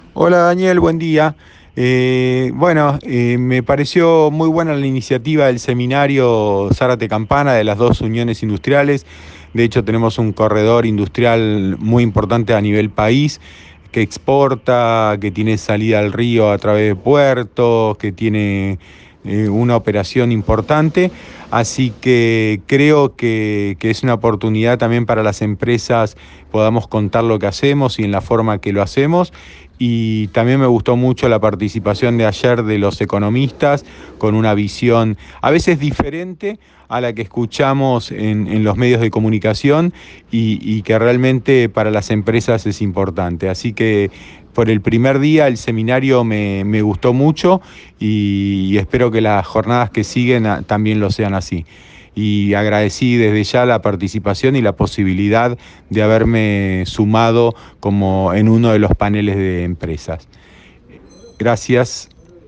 una entrevista por radio EL DEBTAE esta mañana, en el programa Con Zeta